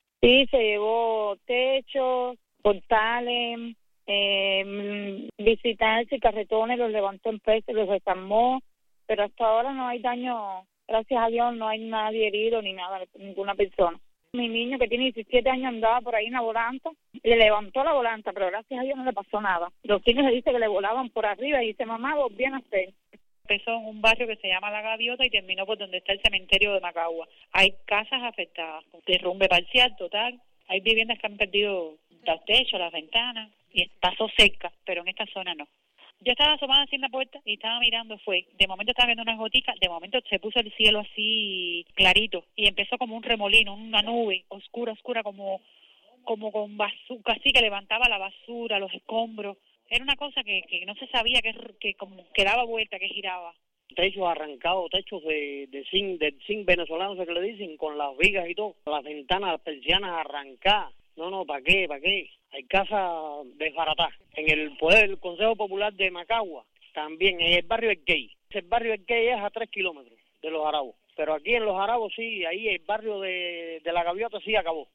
Residentes dijeron a Martí Noticias que hubo afectaciones en viviendas y postes del tendido eléctrico, en los barrios La Gaviota y el Key, donde cuatro viviendas perdieron sus techos y otras siete tienen daños parciales.